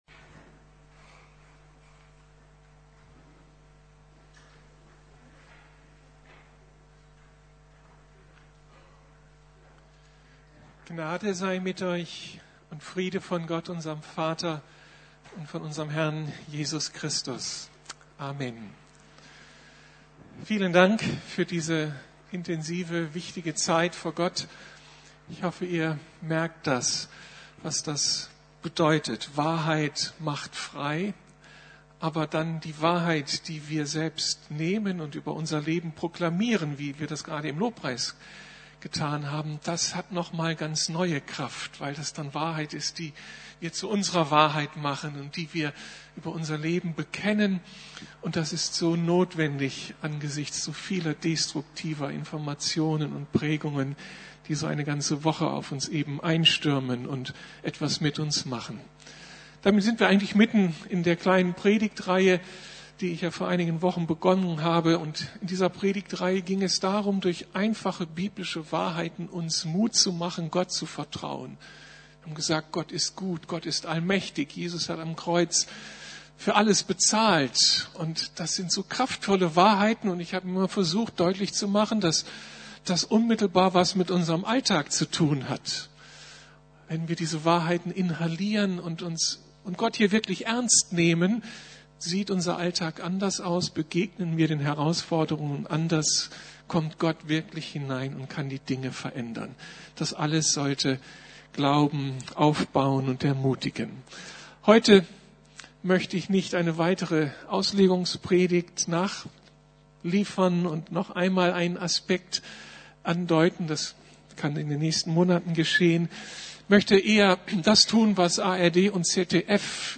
Glaubenserfahrungen heute ~ Predigten der LUKAS GEMEINDE Podcast